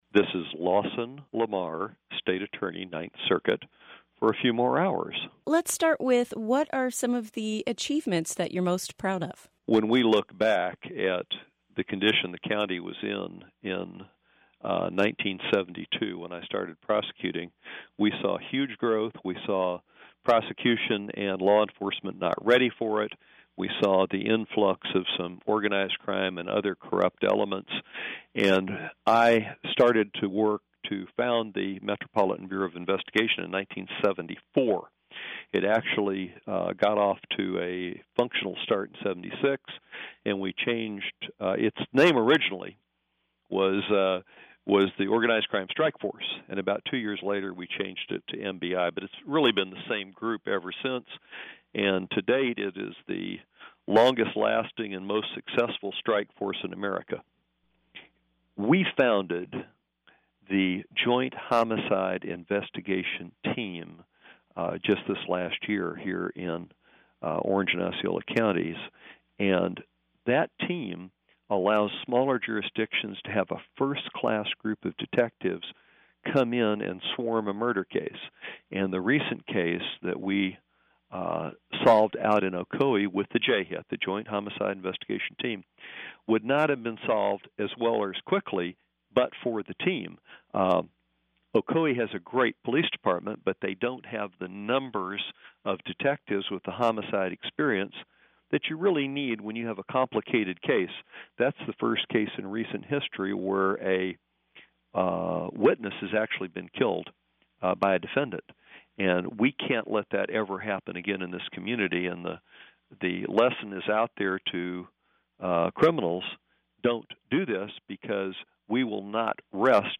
LamarExitInterview.mp3